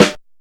Snare (2).wav